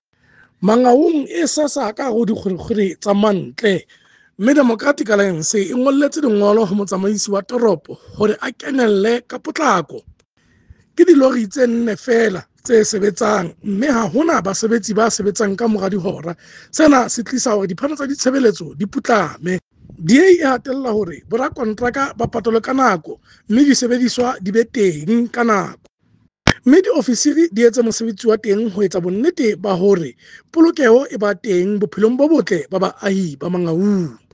Sesotho soundbite by Cllr Kabelo Moreeng